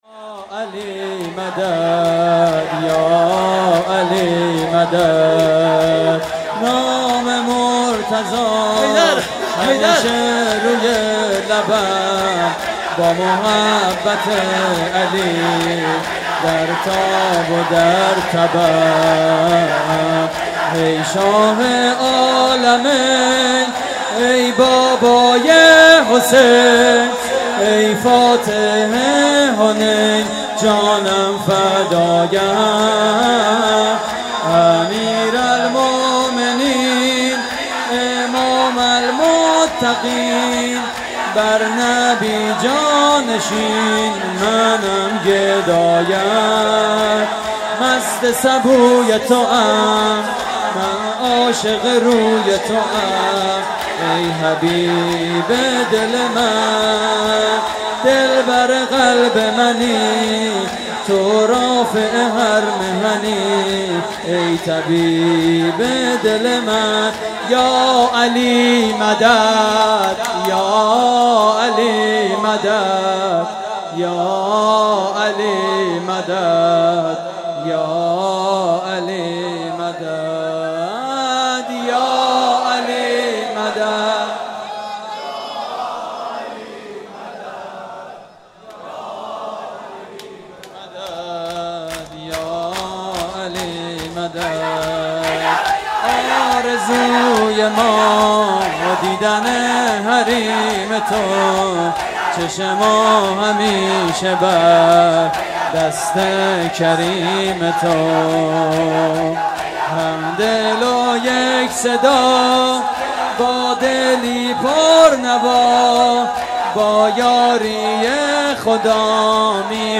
مداحی شب 19 رمضان (شهادت حضرت امیر ع) / هیئت زوار الزهرا (س) - 5 تیر 95
صوت مراسم:
شور: یاعلی مدد